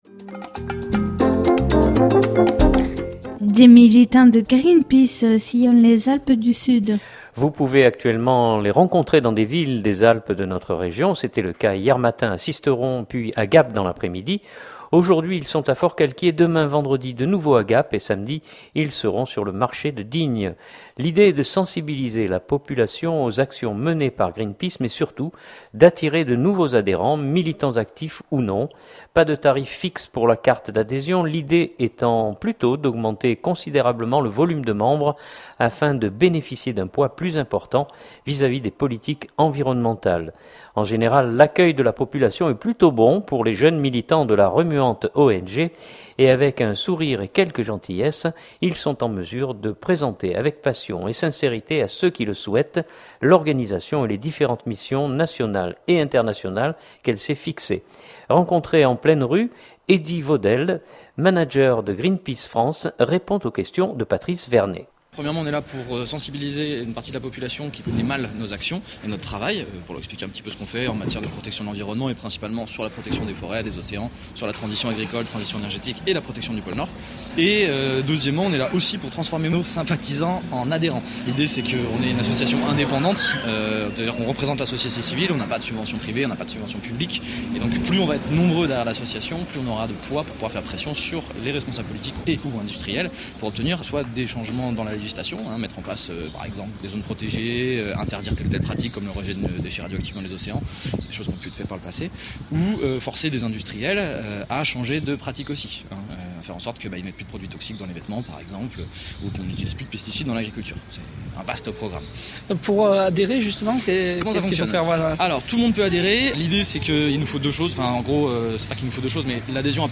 Rencontré en pleine rue